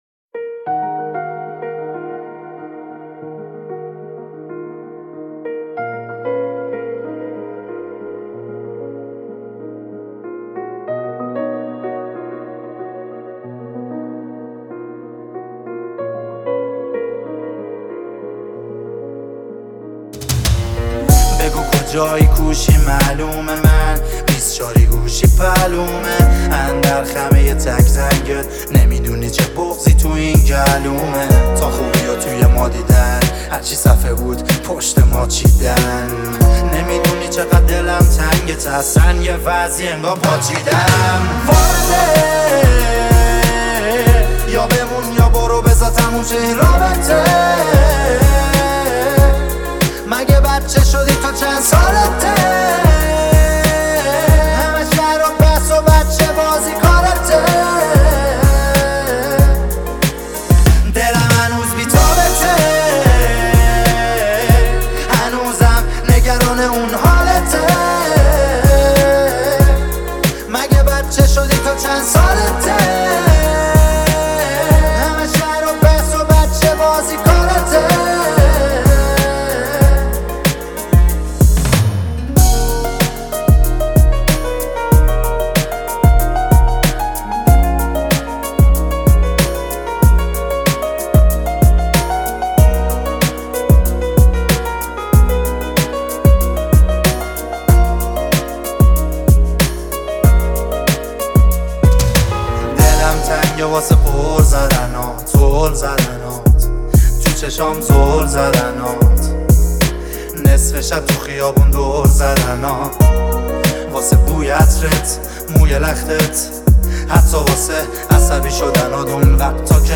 با صدای دلنشین
حس نوستالژیکی را برای مخاطب ایجاد می‌کند.